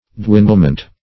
Search Result for " dwindlement" : The Collaborative International Dictionary of English v.0.48: Dwindlement \Dwin"dle*ment\, n. The act or process of dwindling; a dwindling.